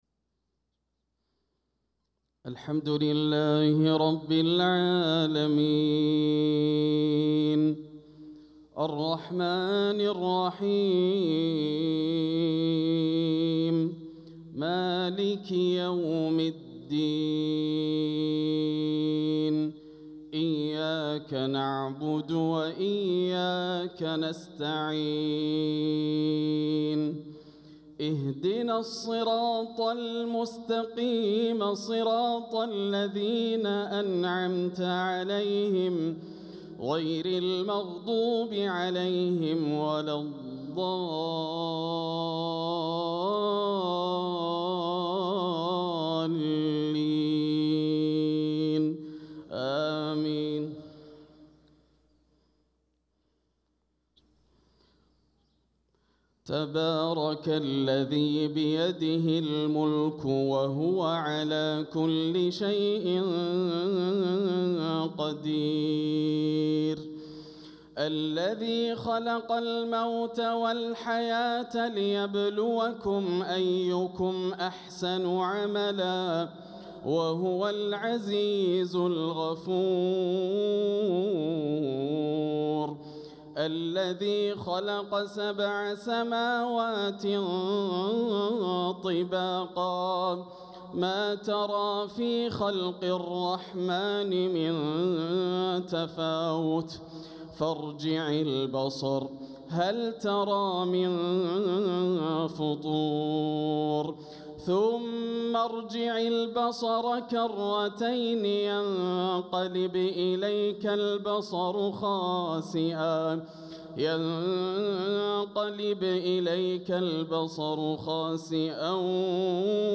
صلاة الفجر للقارئ ياسر الدوسري 4 صفر 1446 هـ